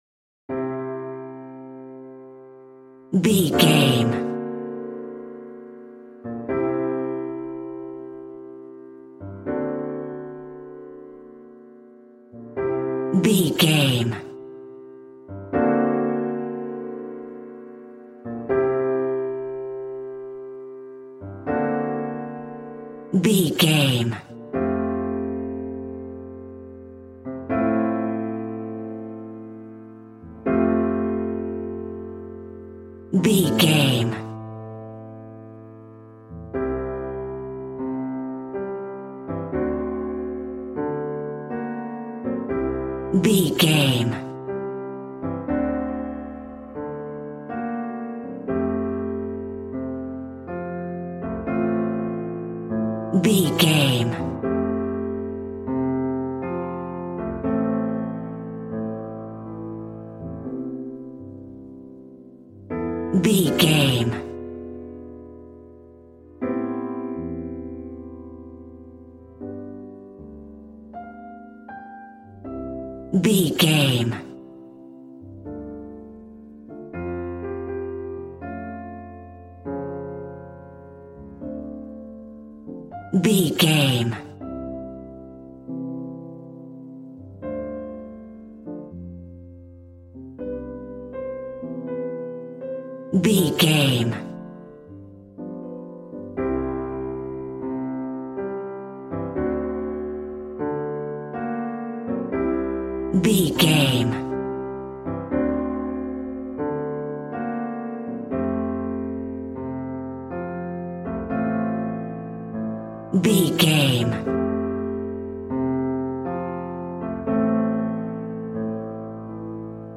Aeolian/Minor
piano
drums